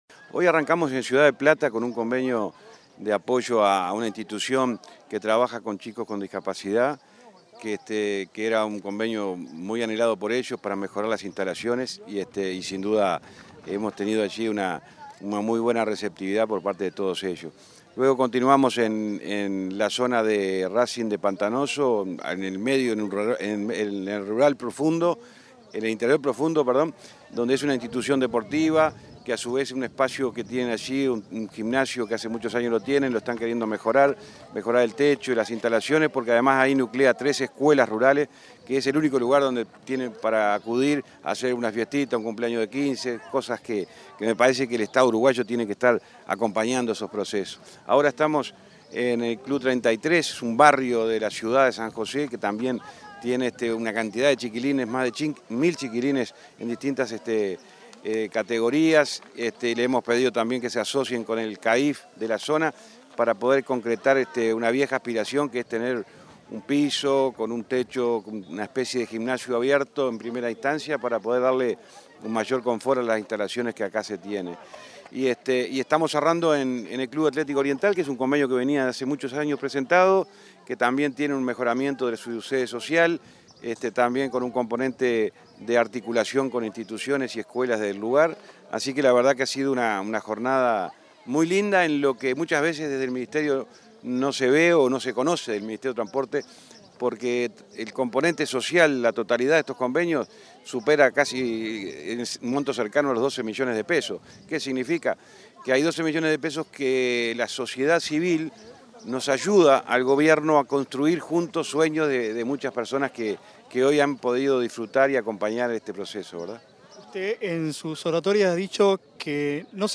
Entrevista al ministro de Transporte, José Luis Falero
Entrevista al ministro de Transporte, José Luis Falero 29/10/2021 Compartir Facebook X Copiar enlace WhatsApp LinkedIn El ministro de Transporte, José Luis Falero, realizó este viernes 29, una recorrida por diversas obras del departamento de San José y firmó varios convenios sociales. En ese contexto, dialogó con Comunicación Presidencial.